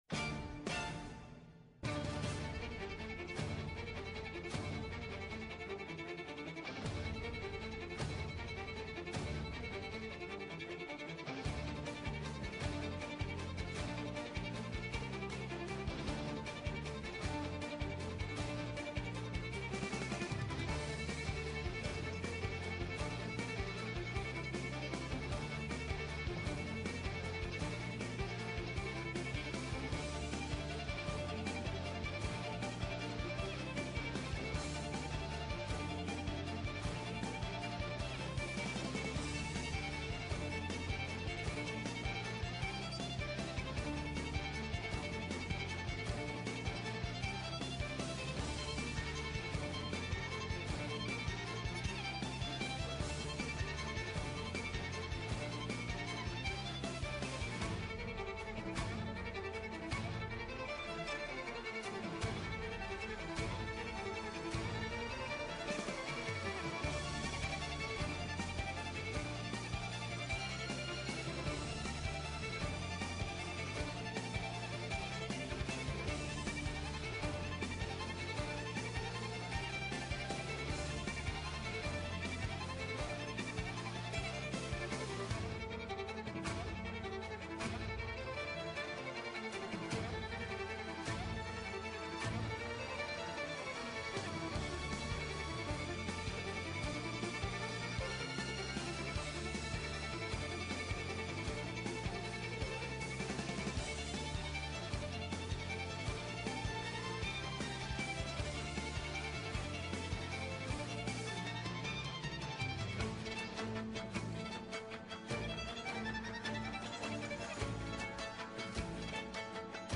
Η εκπομπή για τους νέους καλλιτέχνες που έρχονται στο στούντιο “Ε” της ΕΡΑ και “τζαμάρουν” για μία ολόκληρη ραδιοφωνική ώρα, στο 2019…
ΔΕΥΤΕΡΟ ΠΡΟΓΡΑΜΜΑ Live στο Studio Μουσική Συνεντεύξεις